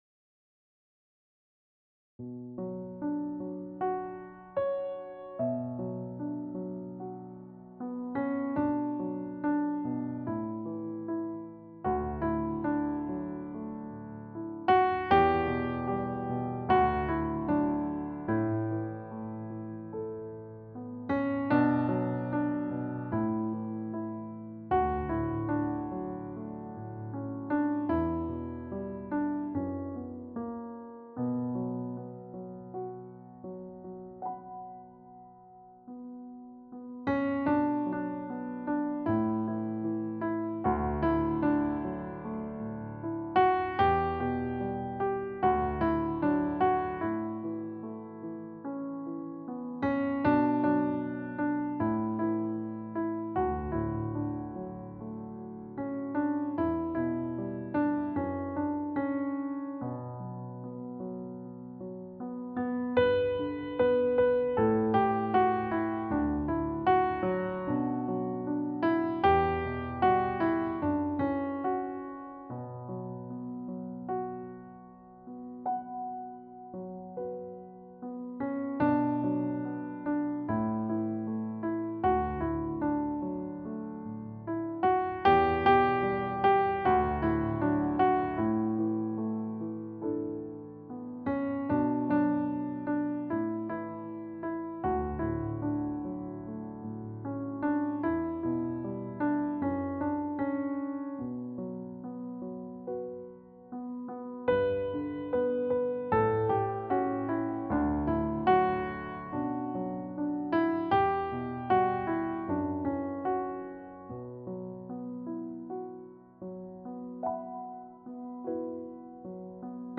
akompaniament